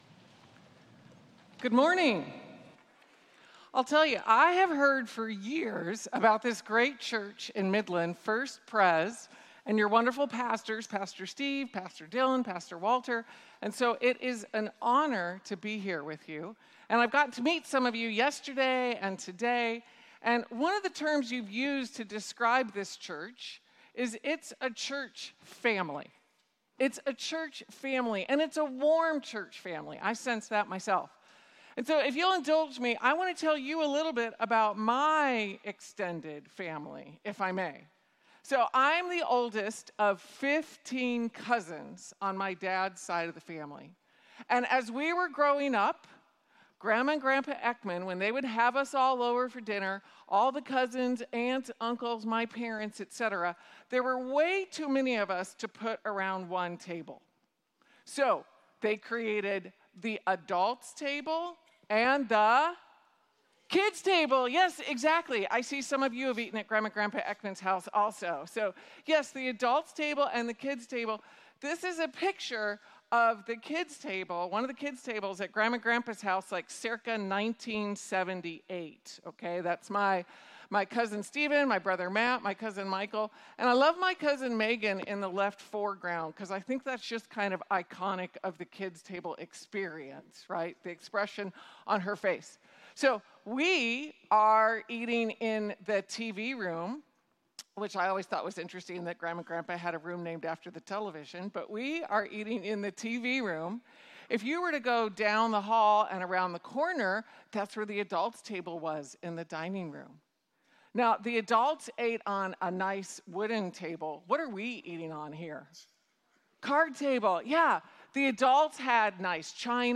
Extending the Table to All Generations (Combined service 8/24/2025)